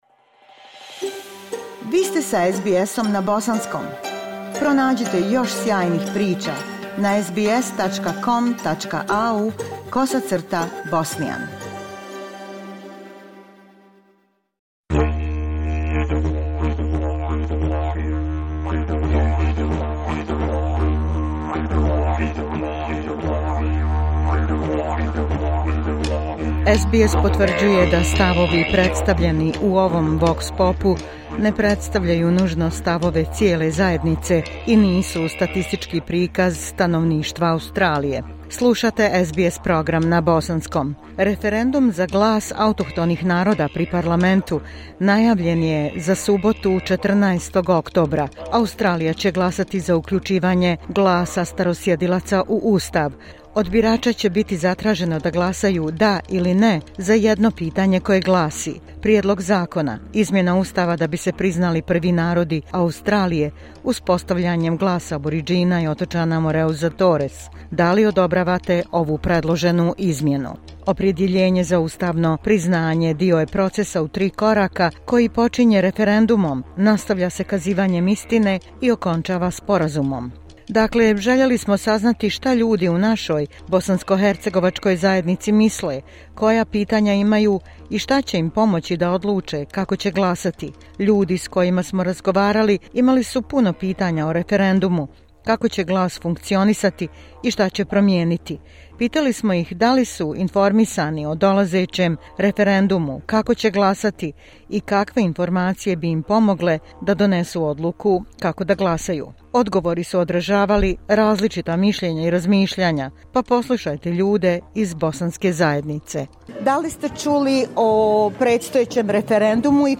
SBS potvrđuje da stavovi predstavljeni u ovom vox pop-u ne predstavljaju nužno stavove cijele zajednice i nisu statistički prikaz stanovništva Australije.